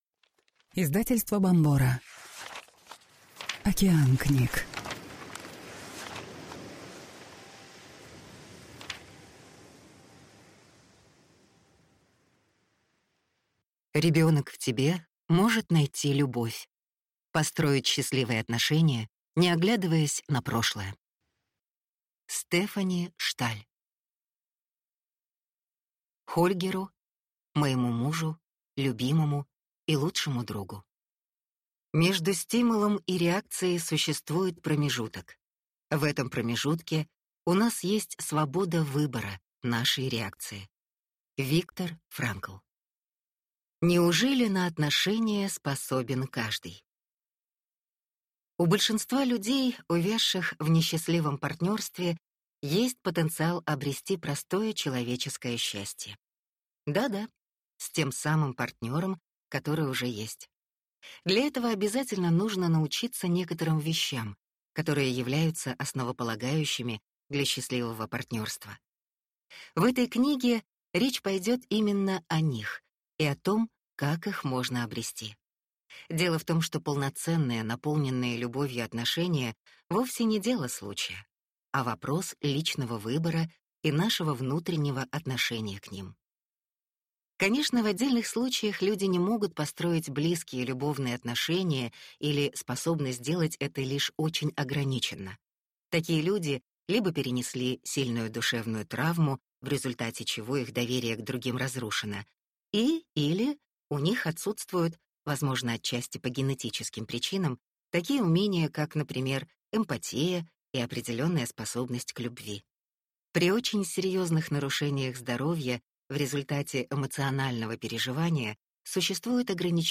Аудиокнига Ребенок в тебе может найти любовь. Построить счастливые отношения, не оглядываясь на прошлое | Библиотека аудиокниг